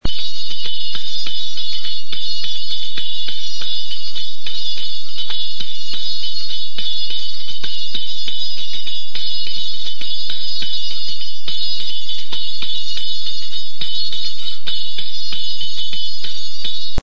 《手振り鉦》
手振り金，ジャガラギともいい，リズムに乗ってシャンシャンというきれいな音を出します。子供から大人まで楽しそうに鳴らす鉦の音は，その振りとともに祭りの賑いを感じさせられます。